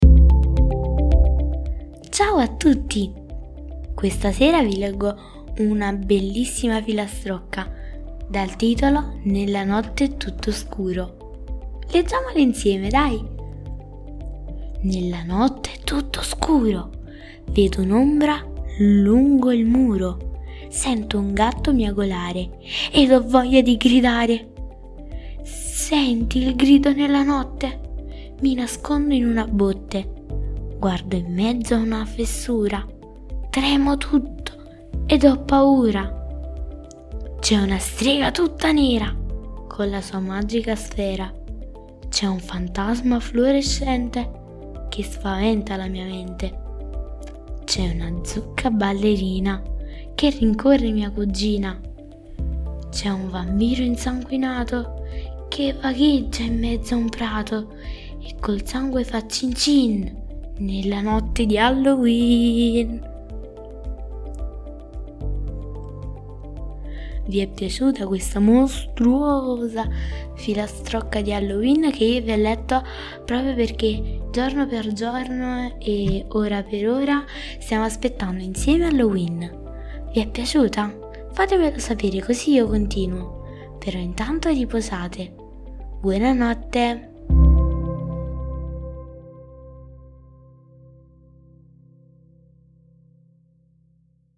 Leggi e ascolta la paurosa filastrocca Nella notte è tutto scuro